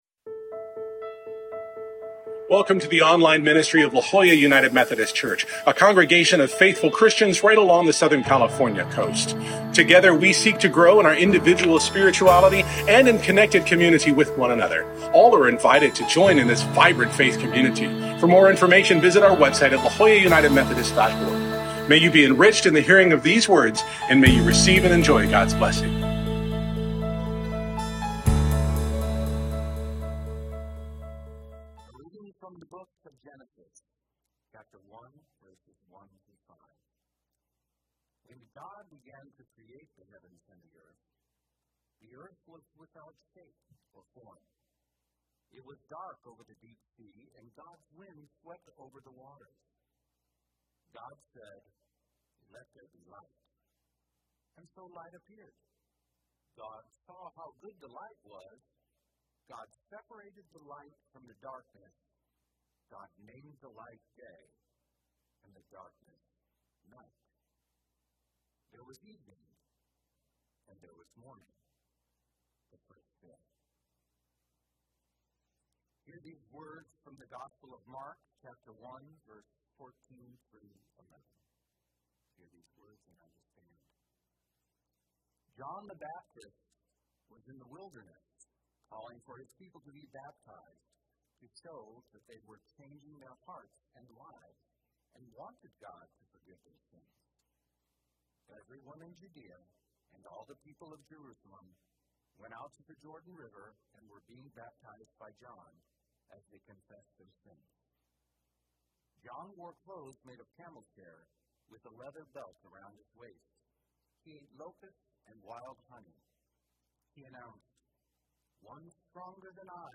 This week we begin a new post-Christmas sermon series called “Why Be Christian?” This first week we begin at the beginning: with the opening verses of the Genesis creation story, and with the baptism of Jesus.